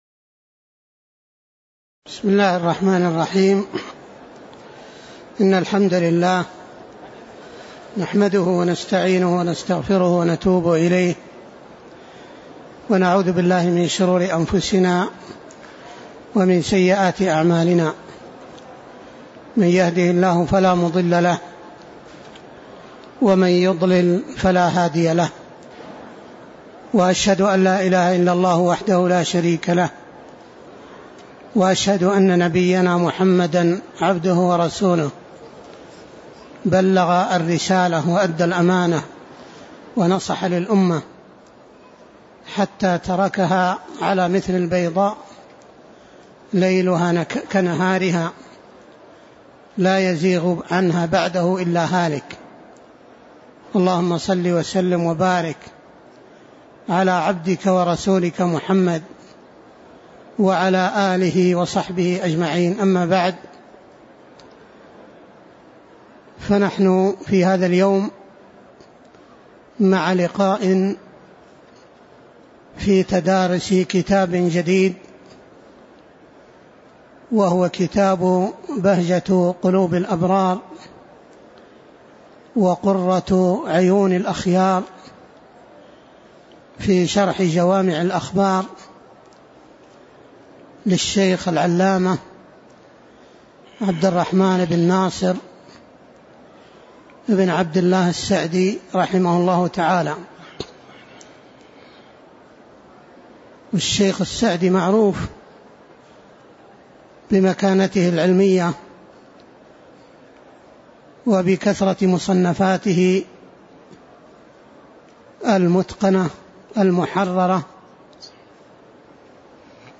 تاريخ النشر ١٤ رجب ١٤٣٥ المكان: المسجد النبوي الشيخ